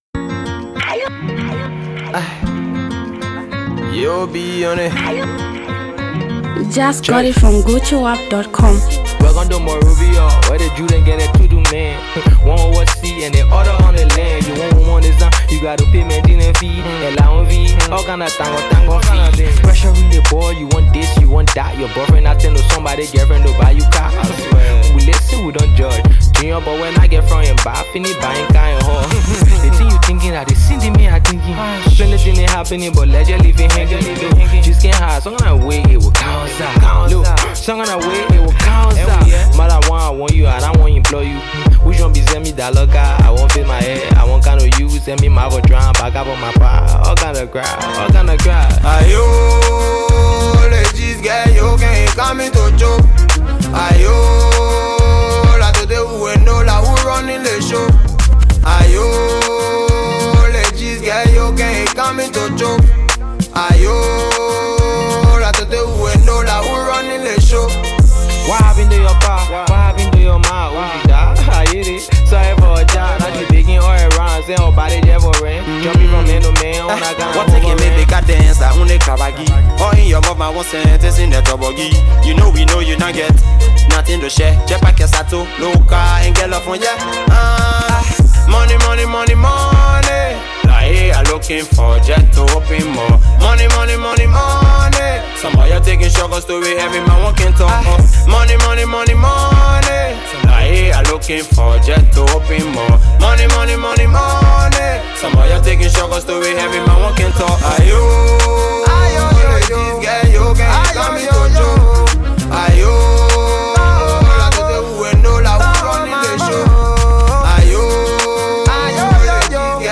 Zambian Mp3 Music
powerful melodic new track